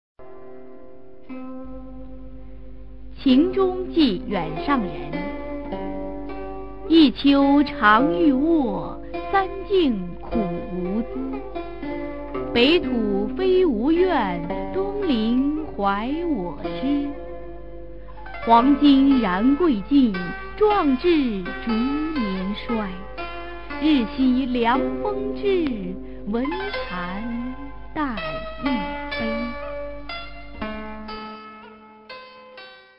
[隋唐诗词诵读]孟浩然-秦中寄远上人a 配乐诗朗诵